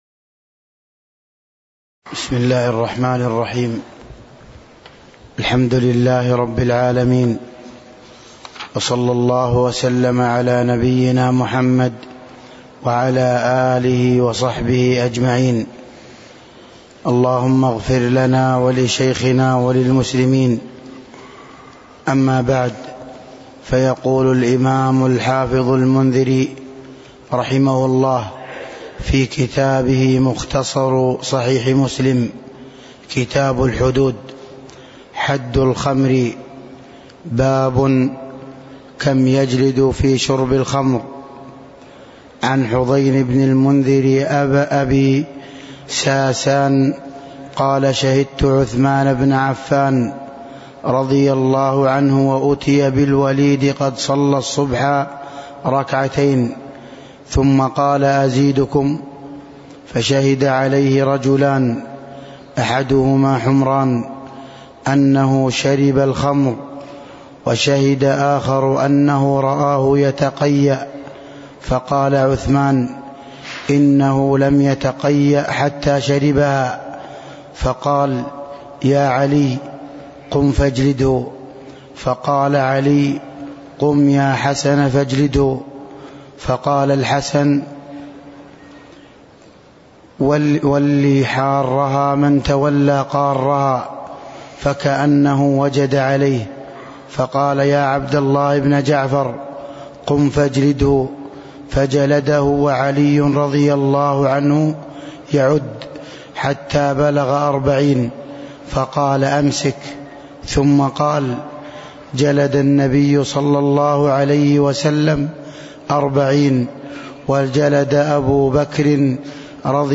تاريخ النشر ١٤ ربيع الأول ١٤٤٣ هـ المكان: المسجد النبوي الشيخ